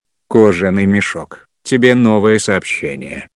на уведомление
голосовые
Отличный и громкий звук на смс